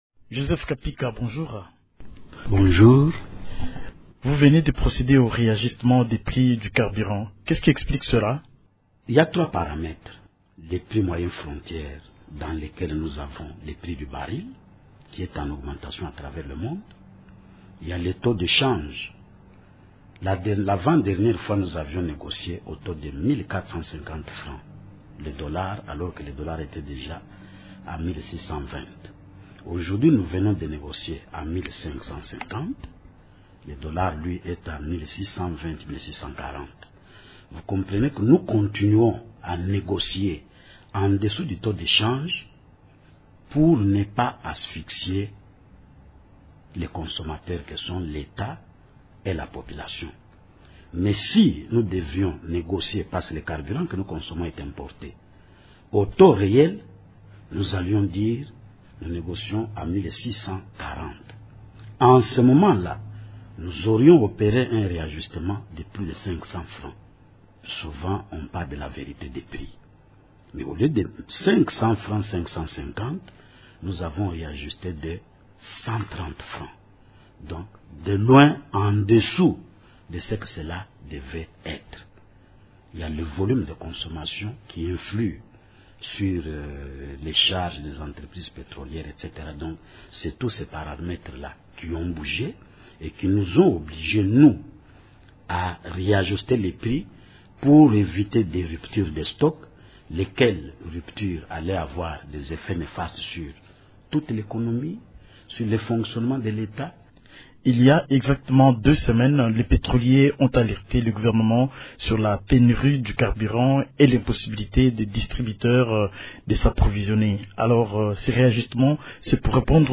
Le ministre Joseph Kapika s’explique sur la majoration du prix du carburant